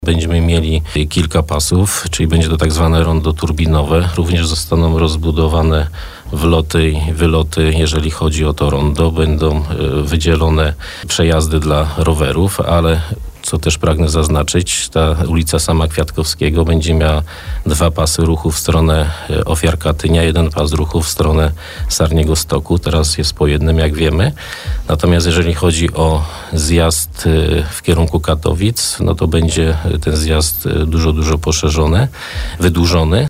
Temat podjęliśmy w trakcie porannej rozmowy.